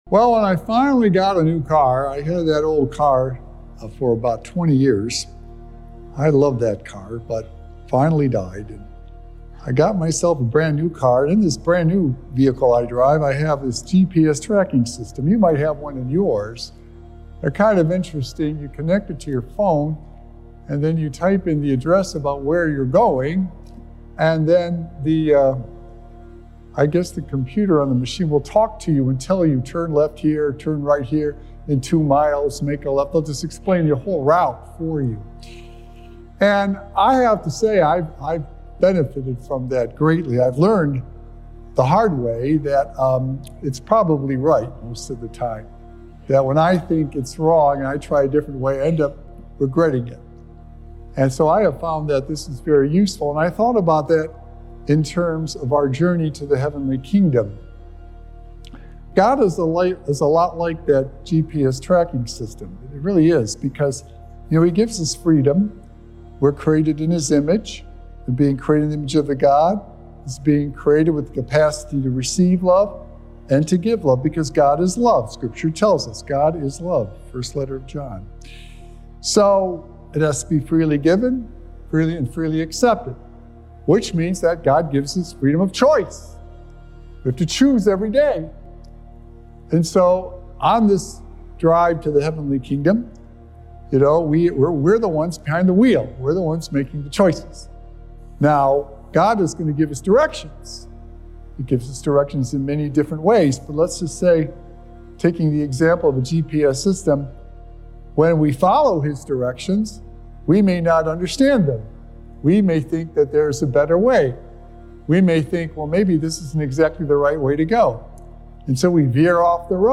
Recorded Live at St. Malachy Catholic Church on Sunday, November 17th, 2024.
Weekly Homilies